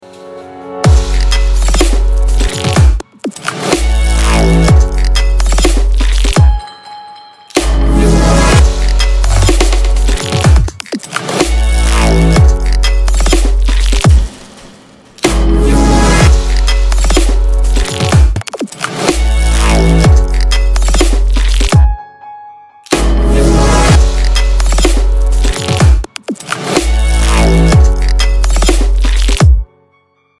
Bass
Dubstep
Стиль: Dubstep